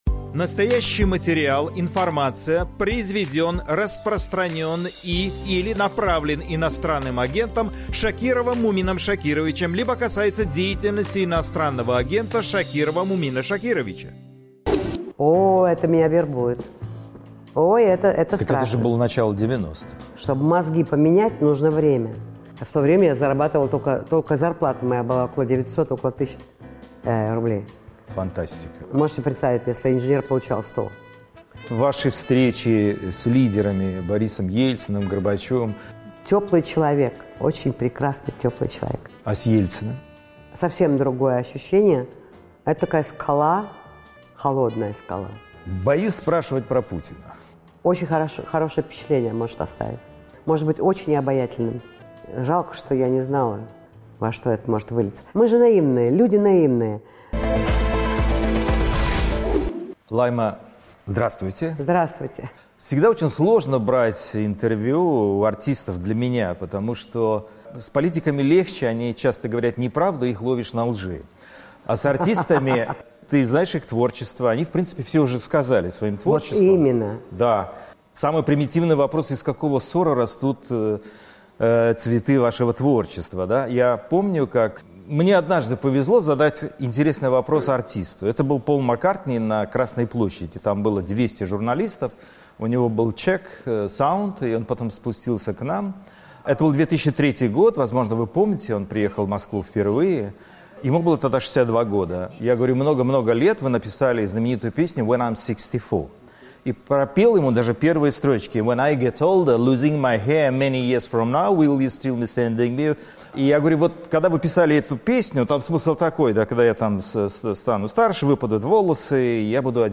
Певица Лайма Вайкуле в интервью Мумину Шакирову.